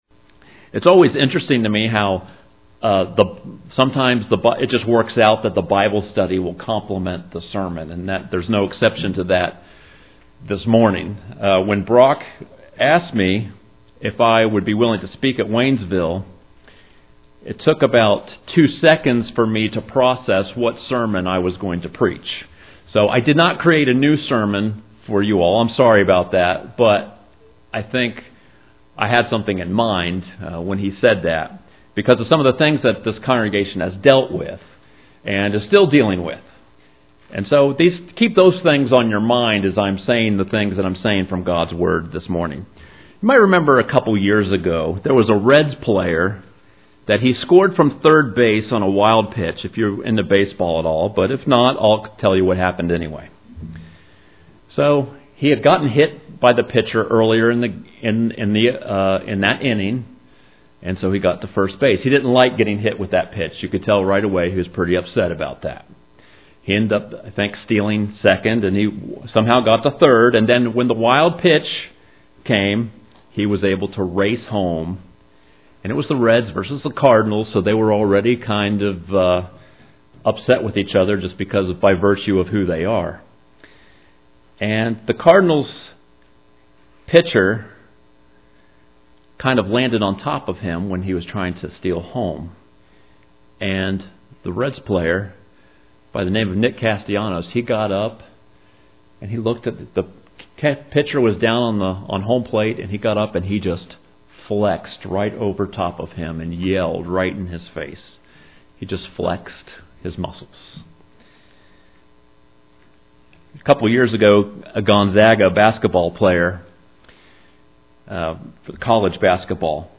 Download or click the lesson recording to hear his message below.